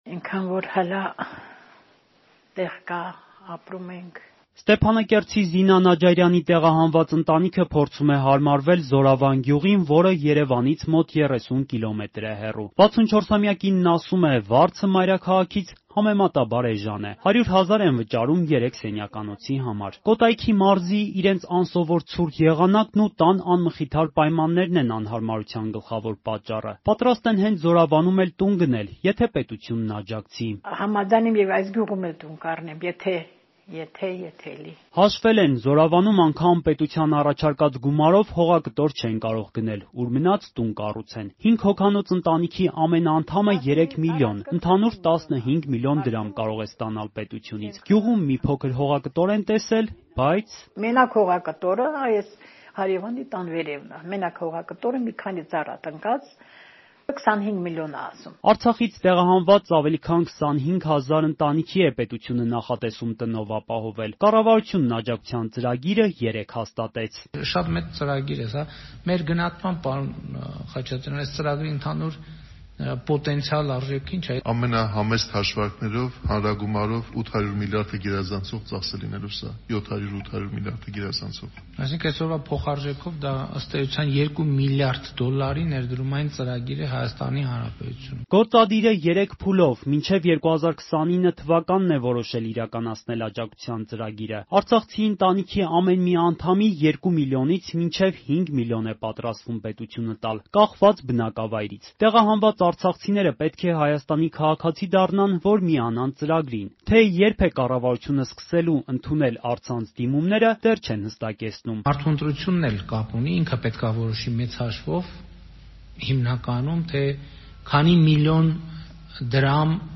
Ռեպորտաժներ
«Մենակ հողակտորը 25 միլիոն է». արցախցի կինը պնդում է՝ պետության առաջարկած գումարով անգամ հողակտոր չեն կարող գնել